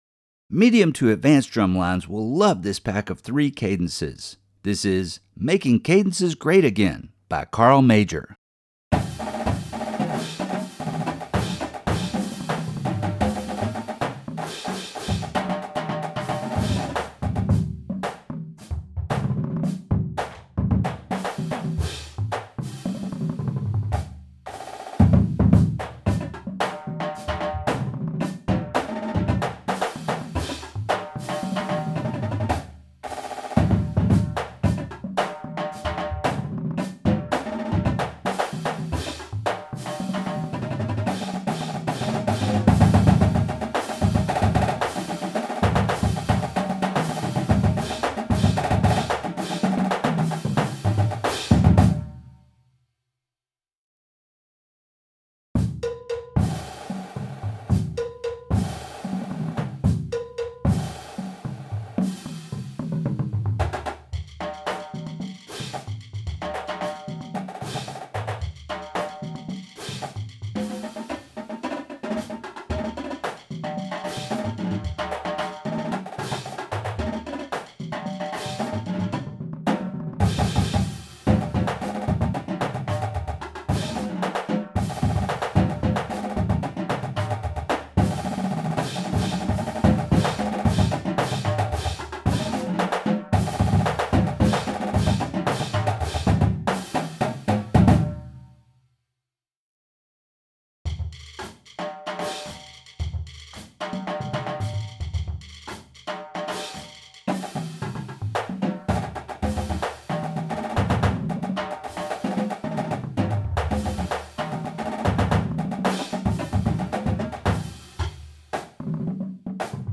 Voicing: Cadence